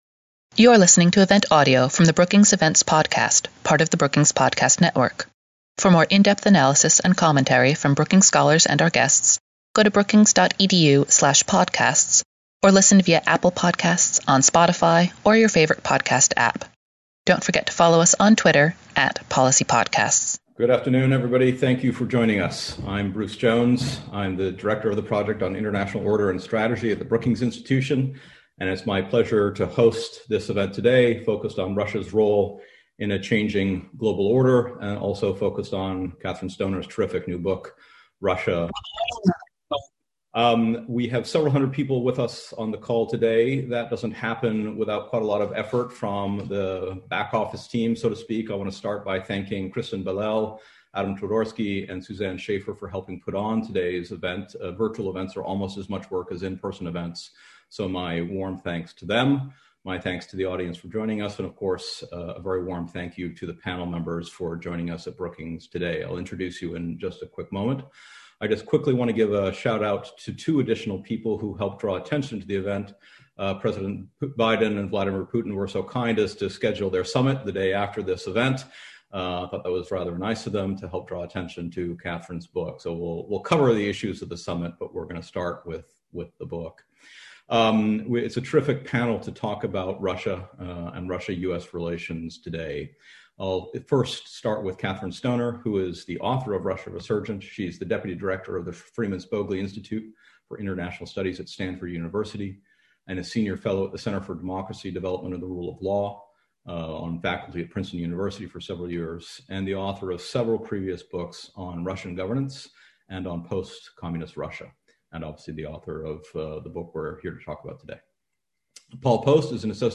On June 15, the Foreign Policy program at Brookings hosted an event exploring these issues and their implications for U.S. policymakers.